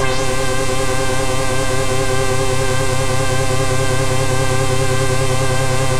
Index of /90_sSampleCDs/Trance_Explosion_Vol1/Instrument Multi-samples/Scary Synth
C3_scary_synth.wav